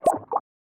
Bamboo Pop v2 Notification3.wav